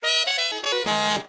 Saxophone.ogg